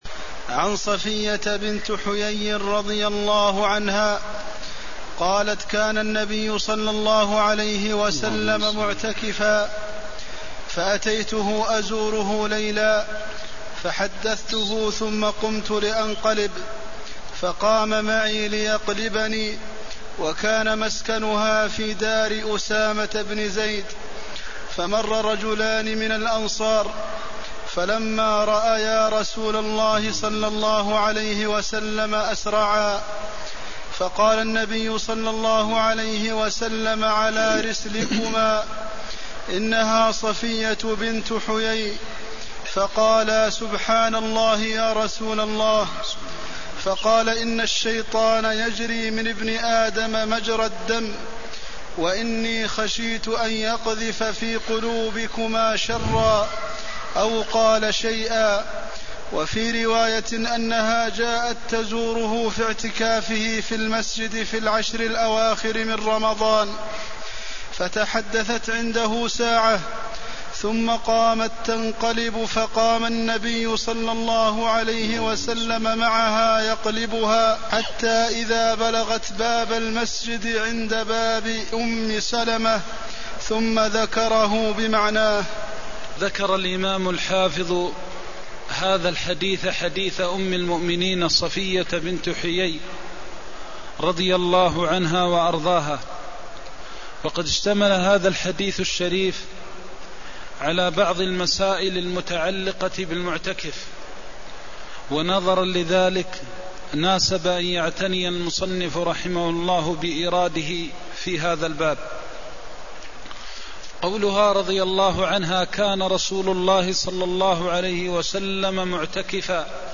المكان: المسجد النبوي الشيخ: فضيلة الشيخ د. محمد بن محمد المختار فضيلة الشيخ د. محمد بن محمد المختار زيارة المرأة زوجها في اعتكافه (201)